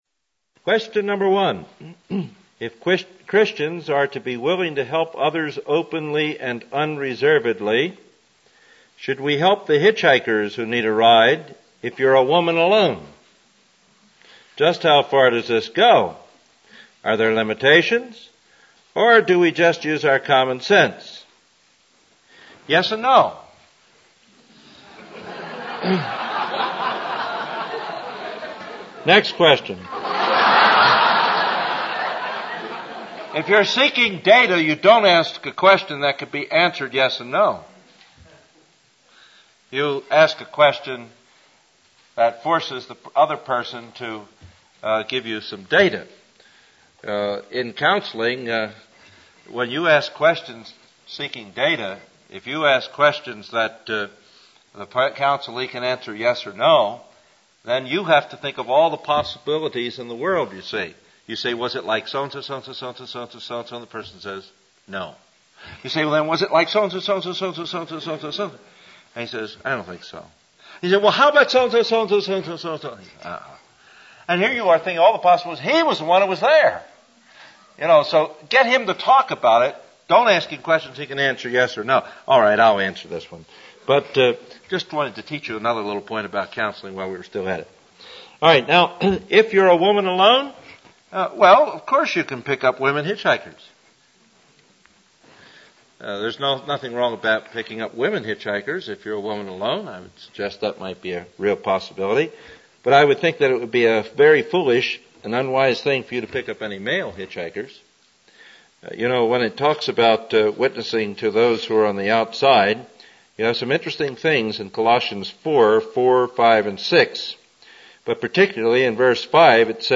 This is the sixth lecture from a classic series on Biblical Counseling Principles